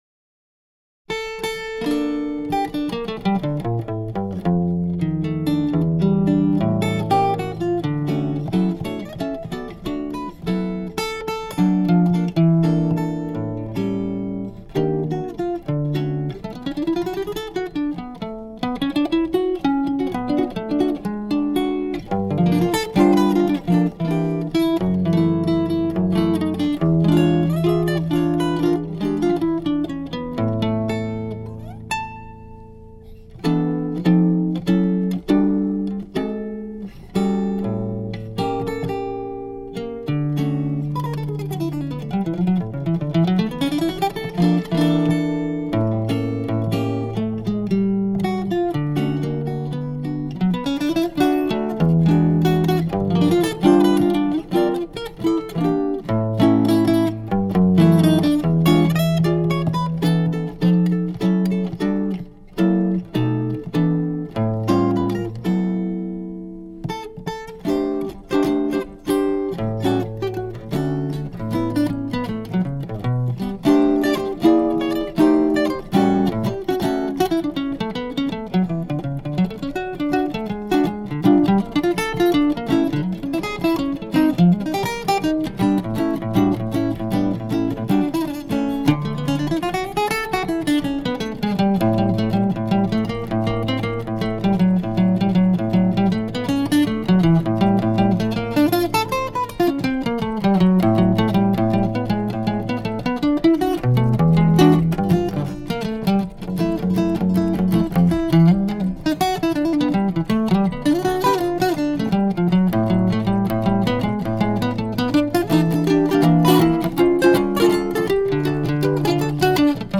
I am in a jazz guitar mood, so I will post this album today.